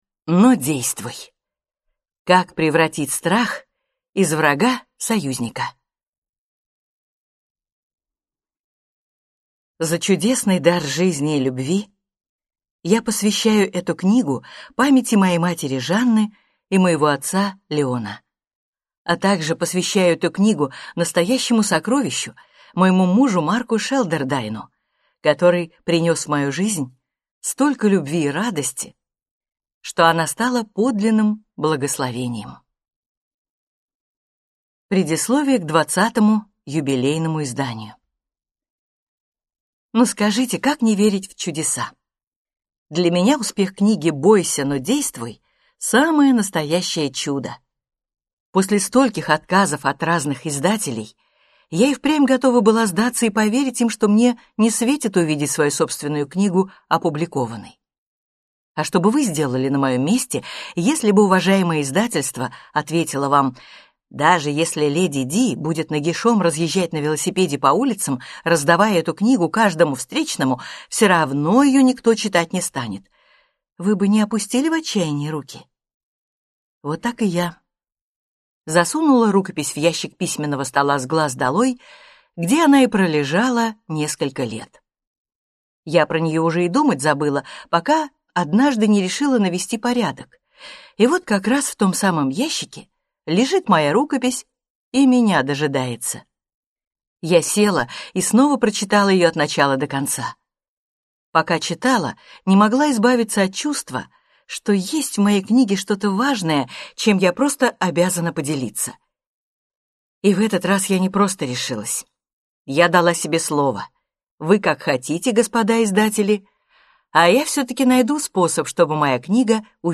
Аудиокнига Бойся… но действуй! Как превратить страх из врага в союзника | Библиотека аудиокниг